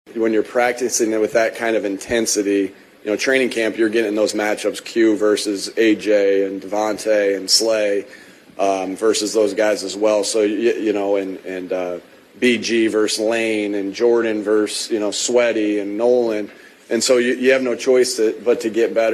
He says the Eagles set the stage for the win by practicing hard.
nws0581-nick-sirianni-practice.mp3